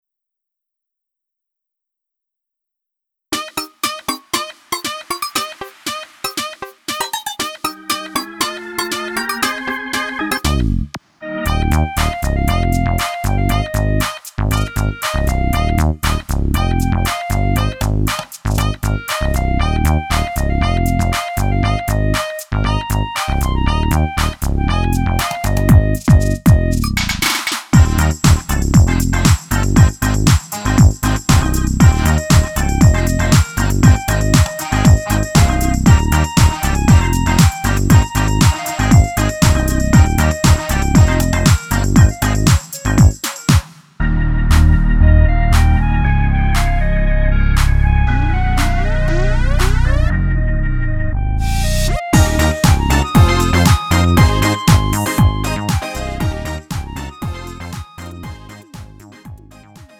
음정 -1키 3:20
장르 가요 구분 Lite MR
Lite MR은 저렴한 가격에 간단한 연습이나 취미용으로 활용할 수 있는 가벼운 반주입니다.